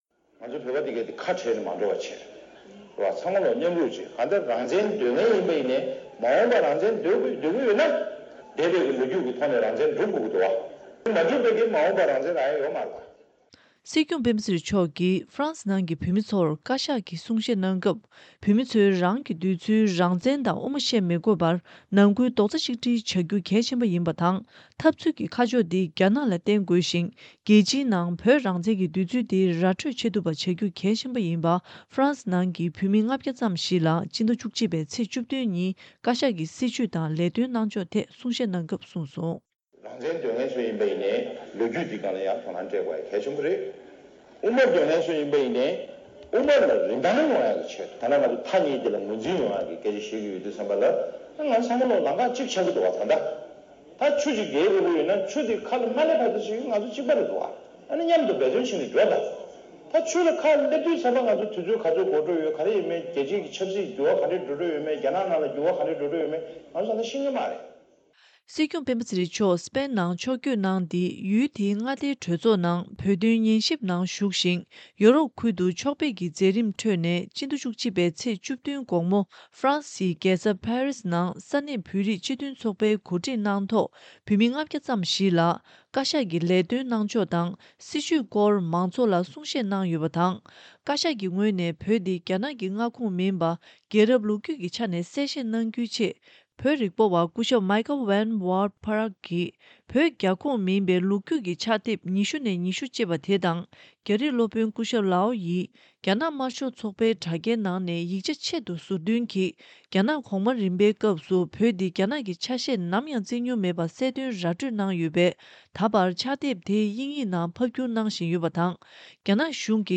སྲིད་སྐྱོང་མཆོག་གིས་ཕ་རན་སི་ནང་གི་བོད་མི་ཚོར་བཀའ་ཤག་གི་གསུང་བཤད་གནང་སྐབས། བོད་མི་ཚོའི་རང་གི་འདོད་ཚུལ་རང་བཙན་དང་དབུ་མ་བཤད་མི་དགོས་པར་ནང་ཁུལ་རྡོག་རྩ་ཆིག་སྒྲིལ་བྱ་རྒྱུ་གལ་ཆེ་ཡིན་པ་དང་འཐབ་རྩོད་ཀྱི་ཁ་ཕྱོགས་འདི་རྒྱ་ནག་ལ་བསྟན་དགོས་ཤིང་། རྒྱལ་སྤྱིའི་ནང་བོད་རང་བཙན་གྱི་འདོད་ཚུལ་འདི་ར་སྤྲོད་བྱེད་ཐུབ་པ་བྱ་རྒྱུ་གལ་ཆེ་ཡིན་པ་ཕ་རན་སི་ནང་གི་བོད་མི་ ༥༠༠ ཙམ་ཞིག་ལ་ཕྱི་ཟླ་ ༡༡ ཚེས་ ༡༧ ཉིན་བཀའ་ཤག་གི་སྲིད་བྱུས་དང་ལས་དོན་ཇི་གནང་གསུང་བཤད་གནང་སྐབས་གསུངས་སོང་།